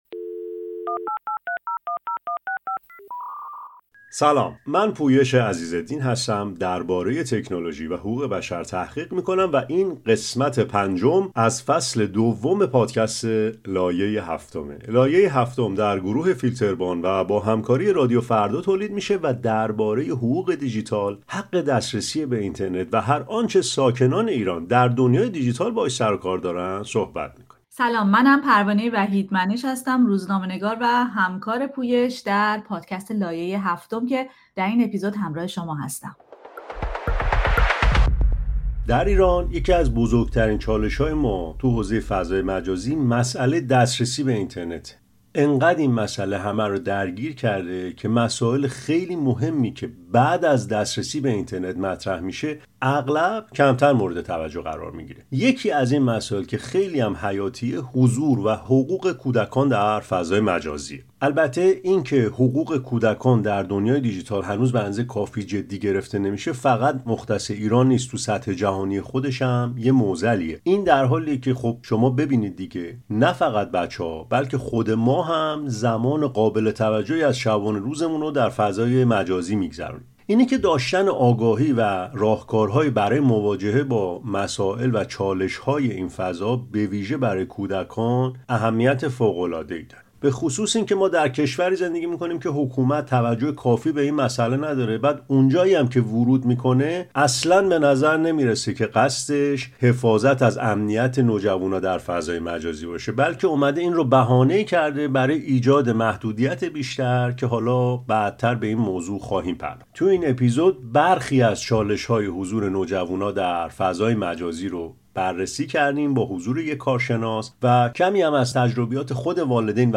در این برنامه، با کارشناسی در این حوزه گفتگو می‌کنیم، تجربه‌های والدین و کودکان را می‌شنویم و به دنبال راهکارهایی برای ایجاد تعادل بین آزادی و امنیت در